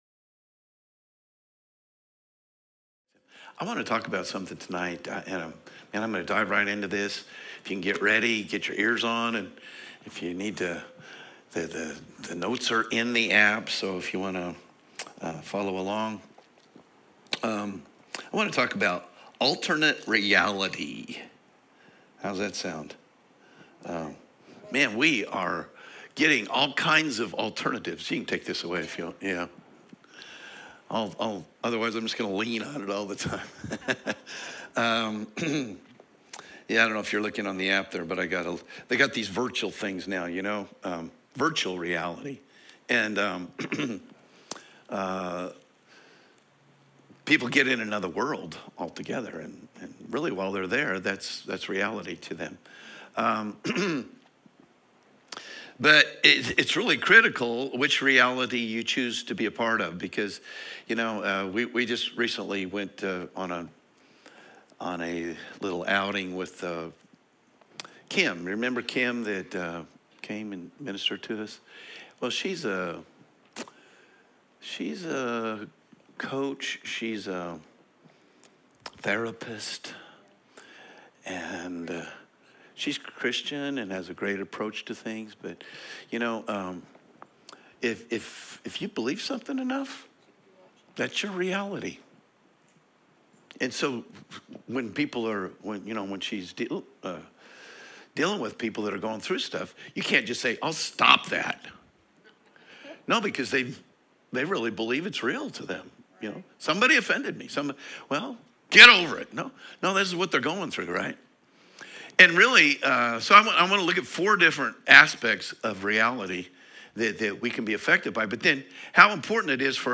Sermons | New Life Church LH